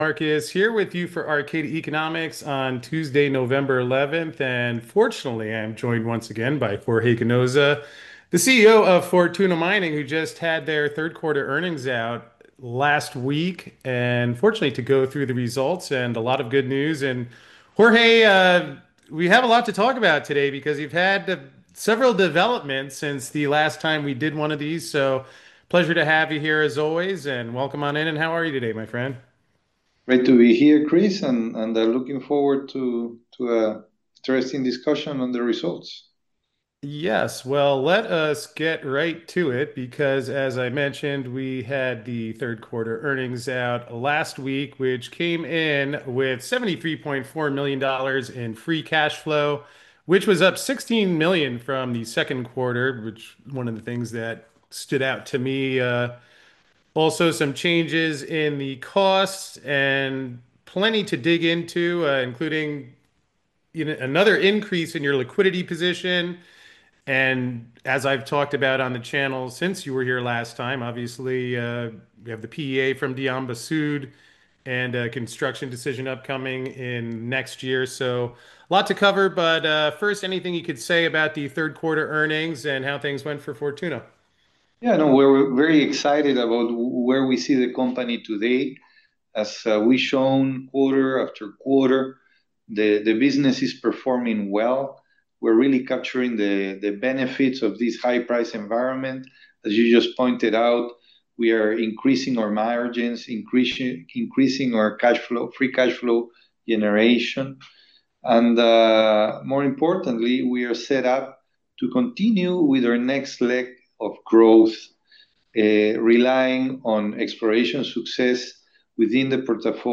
joins me on the show for a live call at 3 p.m. Eastern today, so log on and join us!